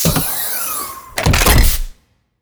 ManipulateDrone.wav